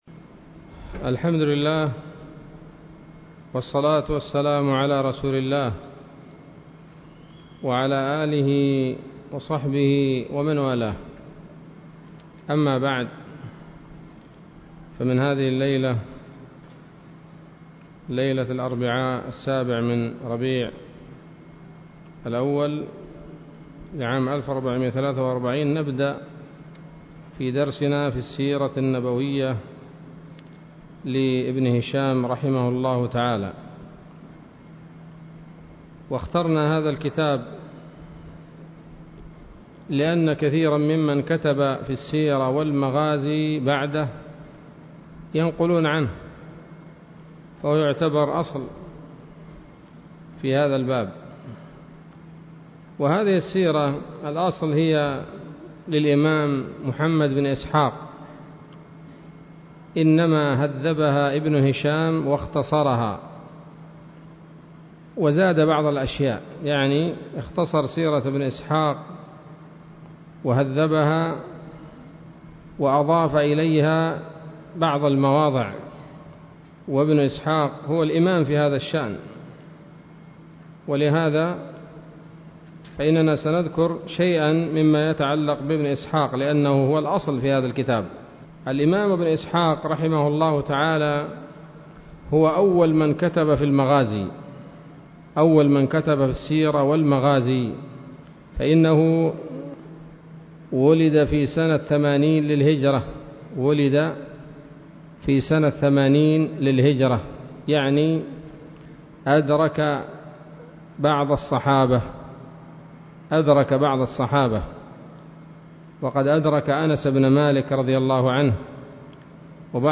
الدرس الأول من التعليق على كتاب السيرة النبوية لابن هشام